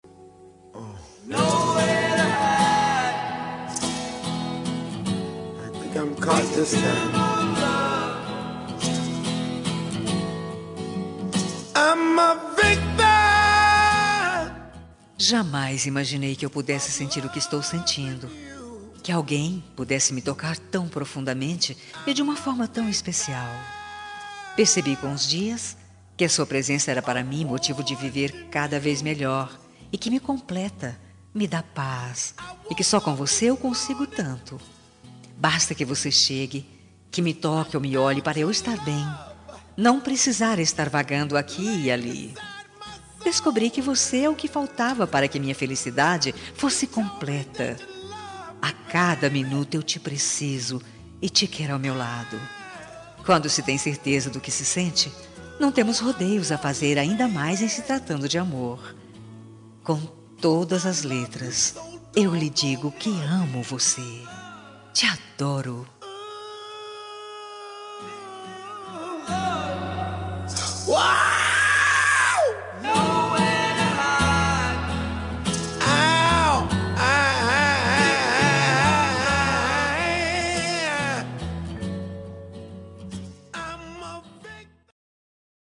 Telemensagem Romântica – Voz Feminina – Cód: 6458